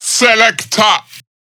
VR_vox_hit_selekta.wav